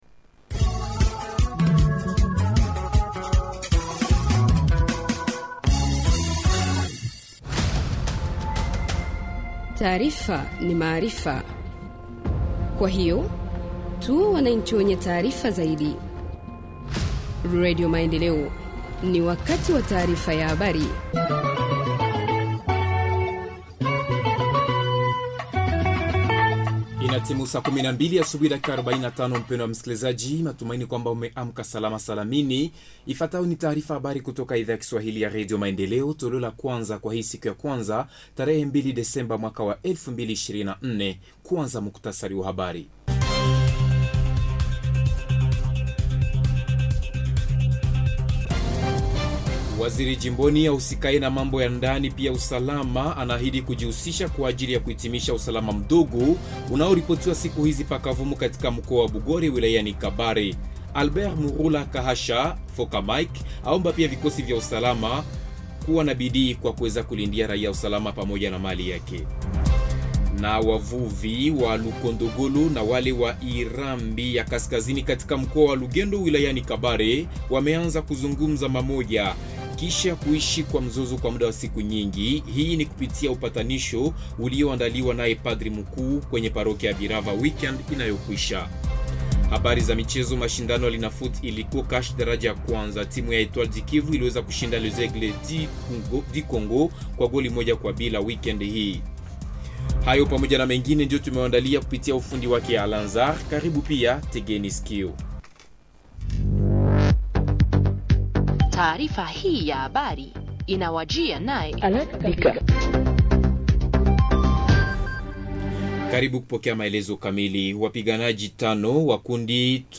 Journal swahili Matin du 02 décembre 2024 – Radio Maendeleo